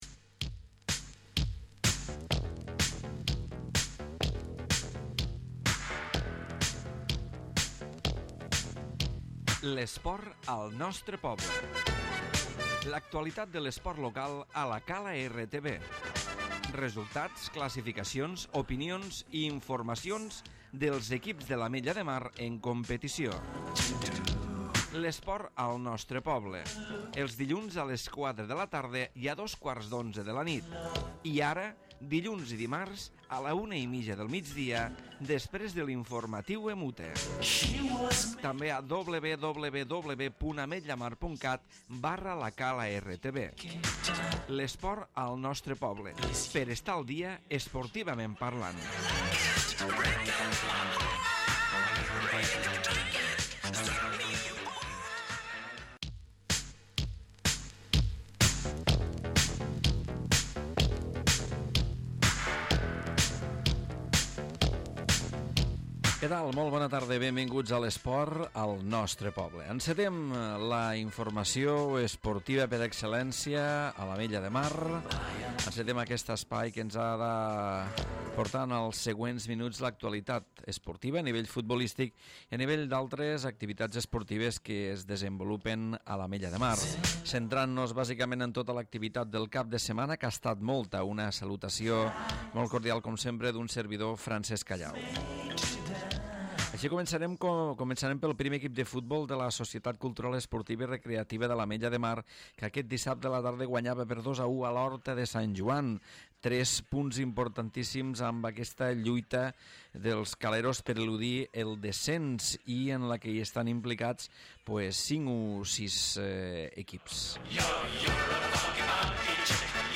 Programa d'actualitat esportiva.